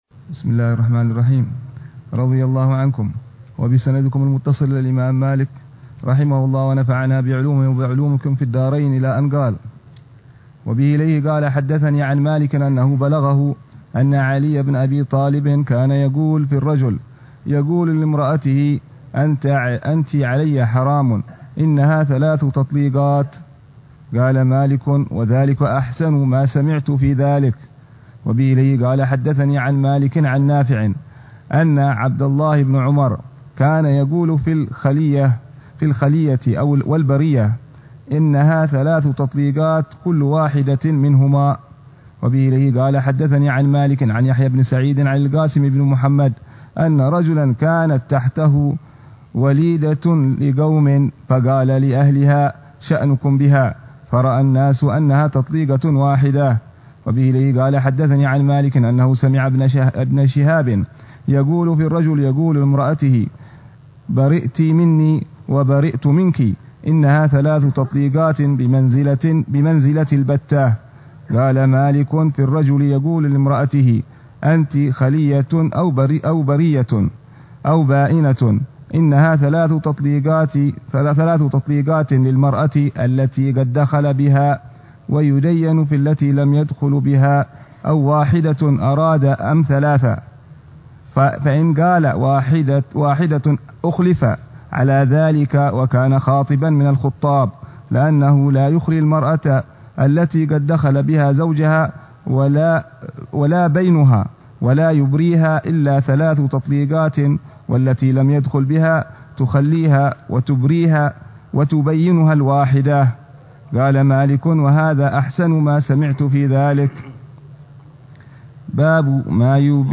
شرح الحبيب العلامة عمر بن محمد بن حفيظ على كتاب الموطأ لإمام دار الهجرة الإمام مالك بن أنس الأصبحي، برواية الإمام يحيى بن يحيى الليثي،